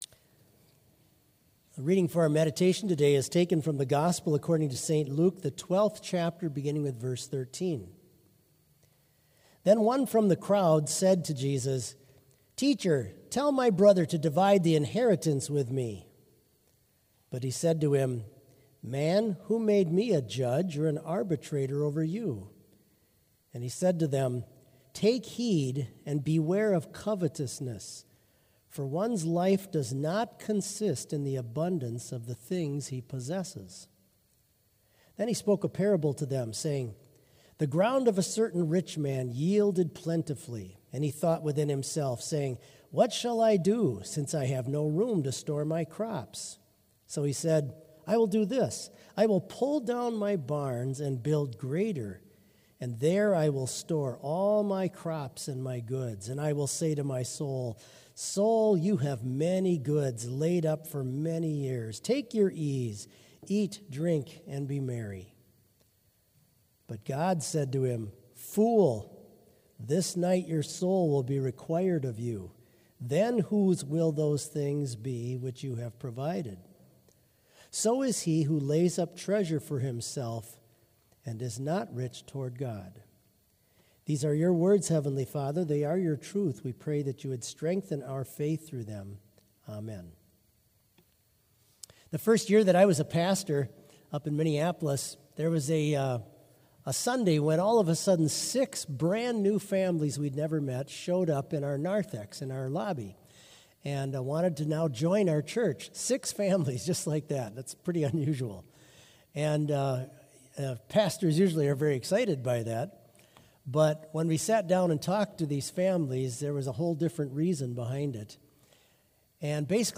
Sermon audio for Chapel - June 14, 2023